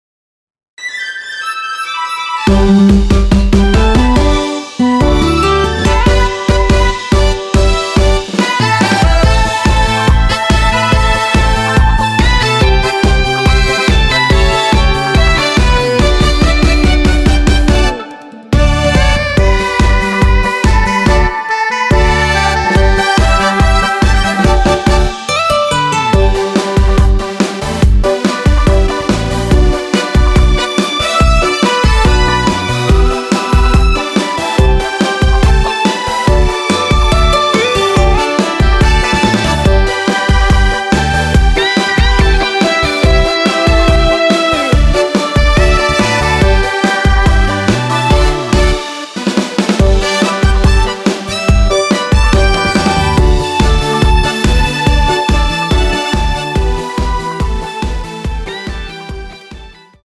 원키에서(+4)올린 멜로디 포함된 MR입니다.
앞부분30초, 뒷부분30초씩 편집해서 올려 드리고 있습니다.
중간에 음이 끈어지고 다시 나오는 이유는